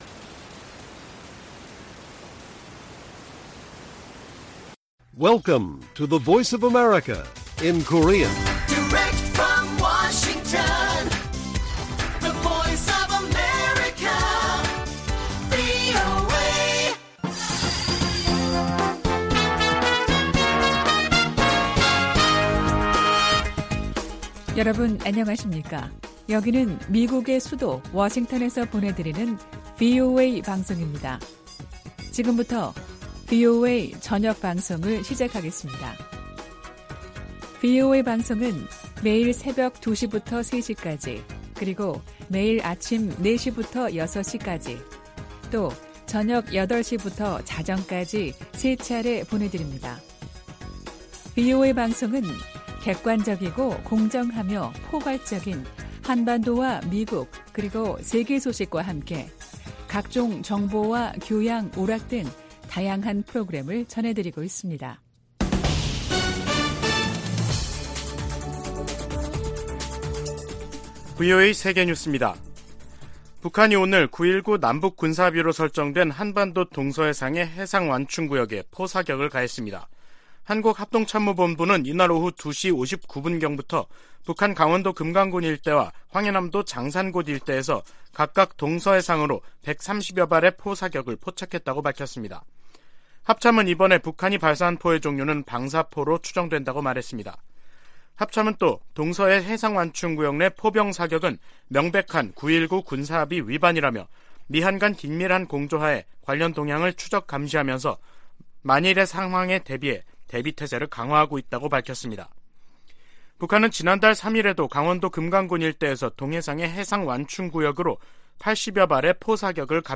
VOA 한국어 간판 뉴스 프로그램 '뉴스 투데이', 2022년 12월 5일 1부 방송입니다. 북한이 오늘 동해와 서해상으로 남북 군사합의를 위반하는 무더기 포 사격을 가했습니다. 백악관 고위 당국자가 북한의 핵 기술과 탄도미사일 역량 발전이 누구의 이익에도 부합하지 않는다면서 중국과 러시아에 적극적인 제재 동참을 촉구했습니다.